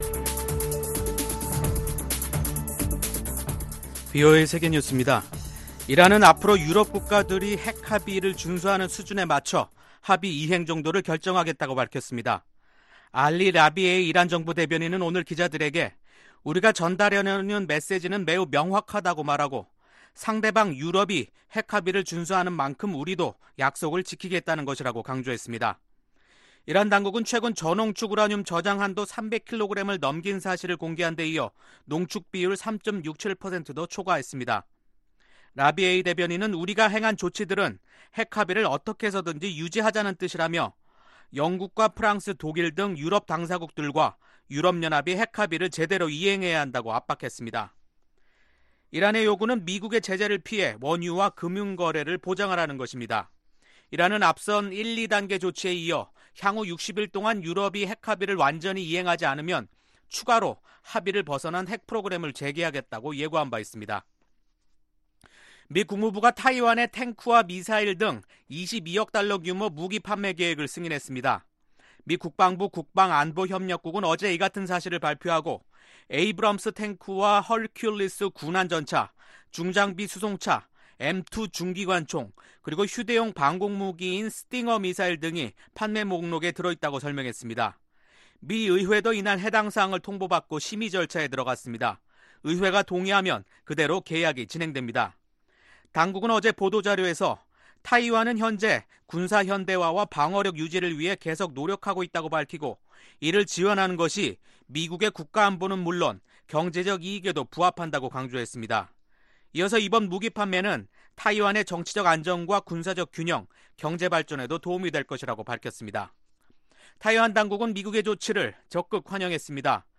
VOA 한국어 간판 뉴스 프로그램 '뉴스 투데이', 2019년 7월 9일 3부 방송입니다. 미국이 중국, 러시아와 함께 대북 정제유 추가 공급을 차단하는 방안을 논의하고 있는 것으로 알려졌습니다. 북한이 사이버 공간에서의 해킹을 통해 돈을 벌고 있다고 유엔 안보리 대북제제 위원회 제재 전문가가 밝혔습니다.